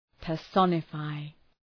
{pər’sɒnə,faı}